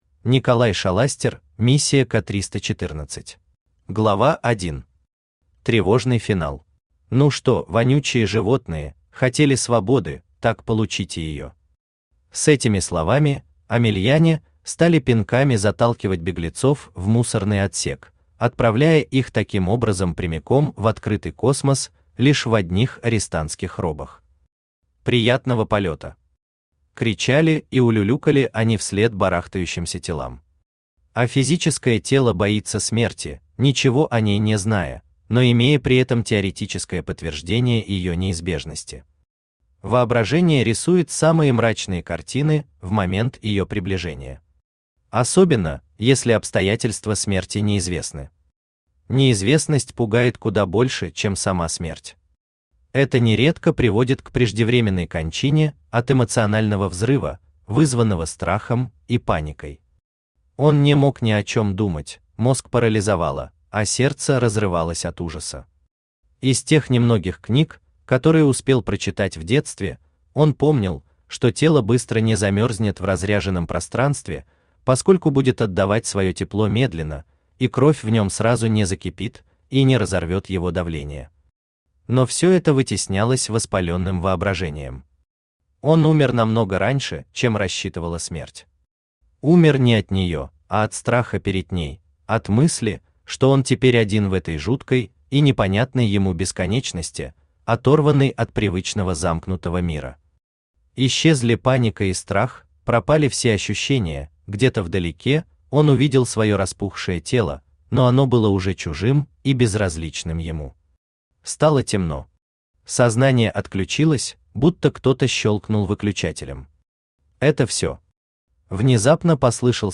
Аудиокнига Миссия К-314 | Библиотека аудиокниг
Aудиокнига Миссия К-314 Автор Николай Николаевич Шоластер Читает аудиокнигу Авточтец ЛитРес.